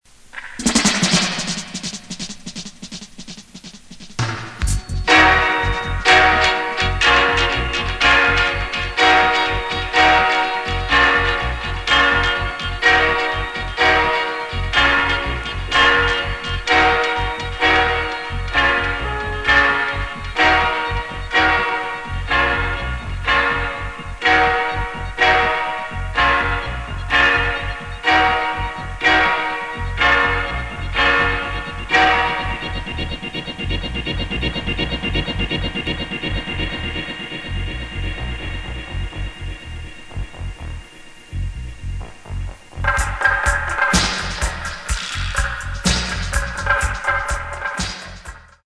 Recorded: Ariwa Studio